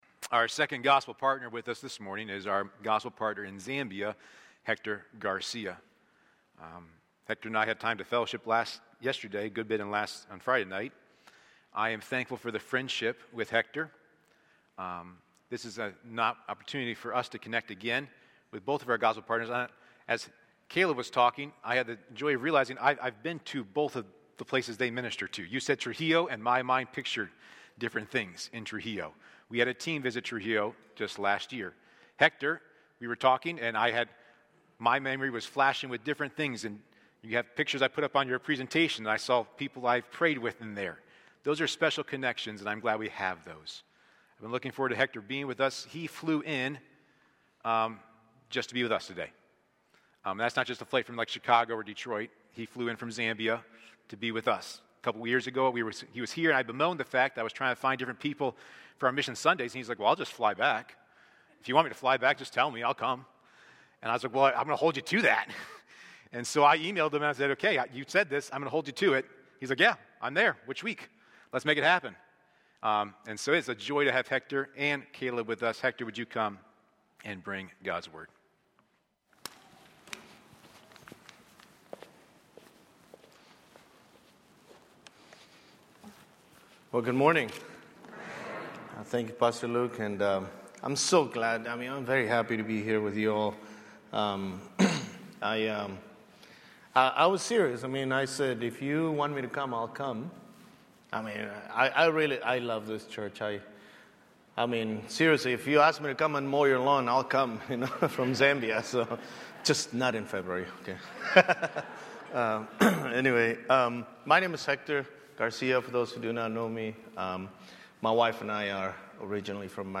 A sermon from the series "Missions Emphasis."